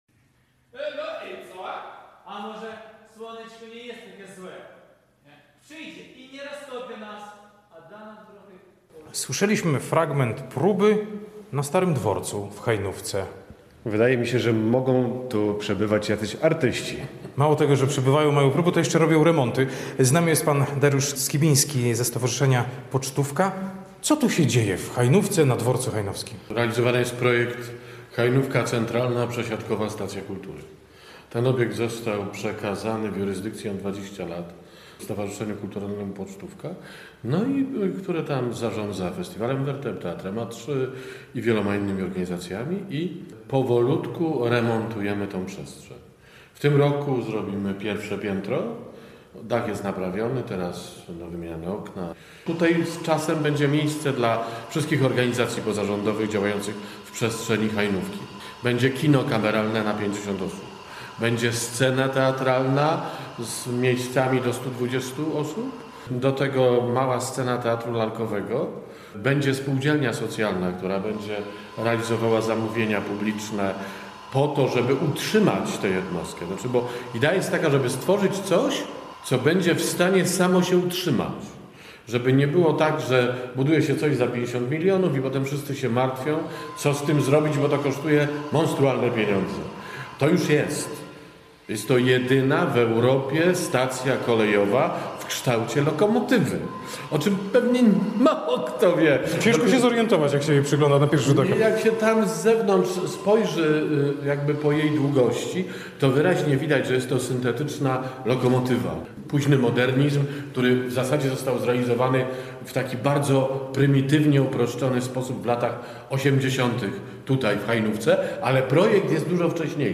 Zaglądamy na dworzec w Hajnówce, a tam...